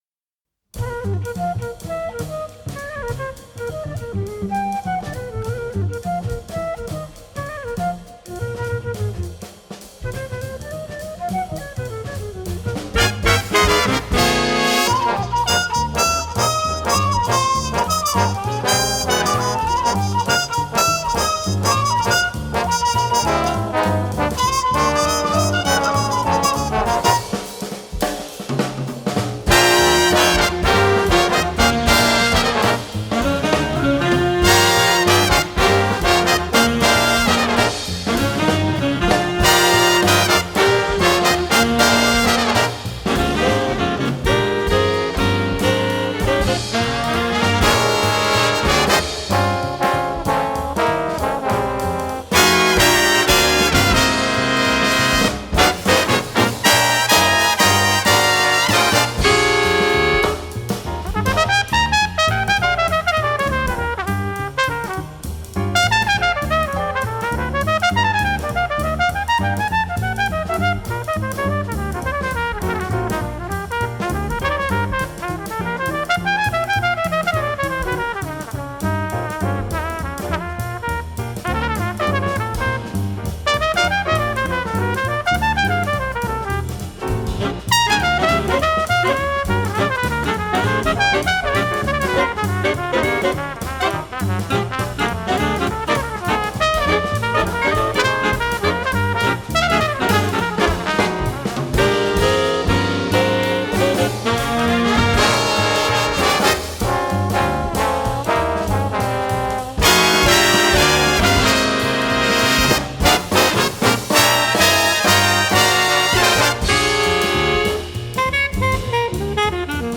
big band
Saxophones
Trompettes
Trombones
Piano
Contrebasse
Batterie